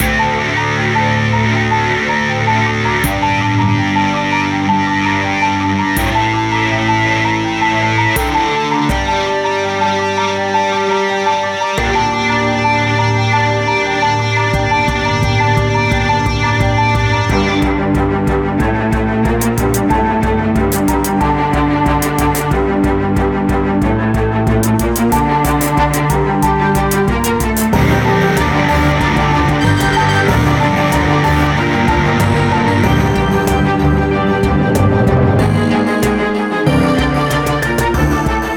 For the rough mix, the drums typically sit on the same track to save CPU power, like the examples in this article.
In this example, the bass drum isn’t too bad, but the cymbal sound doesn’t always work because it is a shorter high-hat sound with a quick attack and release, not the full deep crash needed for the orchestra hits. Even later in the excerpt the cymbal doesn’t work as well as the original sample.